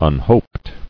[un·hoped]